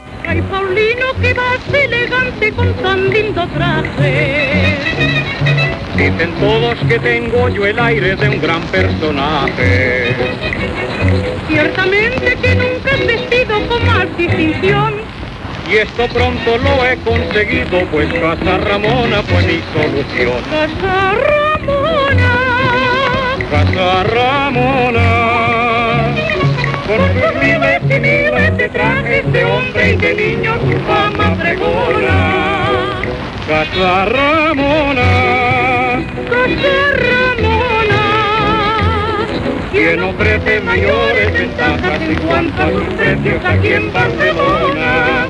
Anunci cantat